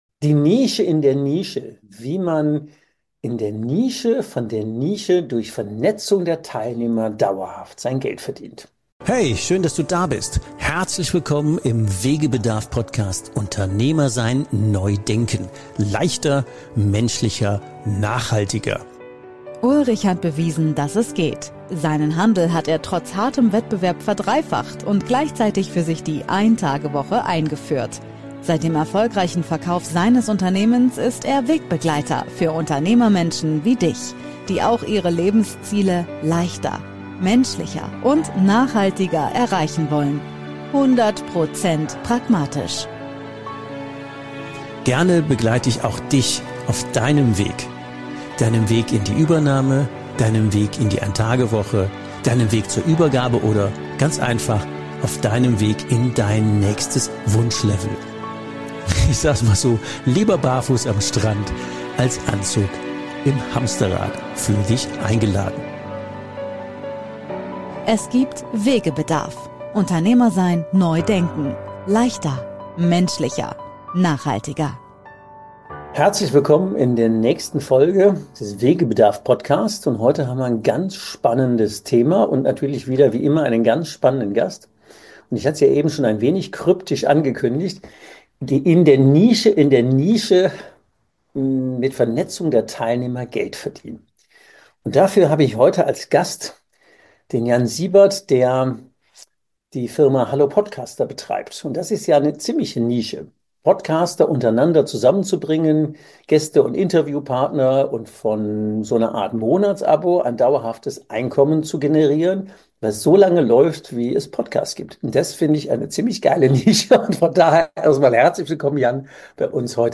In diesem Interview erzählt er wie.